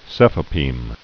(sĕfə-pēm)